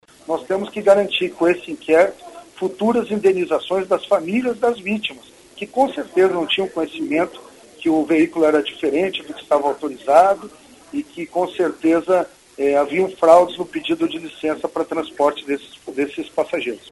O secretario Estadual da Segurança Pública do Paraná, o delegado Fernando Francischini, esteve reunido na tarde desta segunda-feira, 16, com a imprensa, onde relatou que a empresa envolvida no acidente na Serra Dona Francisca Costa & Mar, não estava com a documentação em dia e apresentava vários erros no trabalho prestado.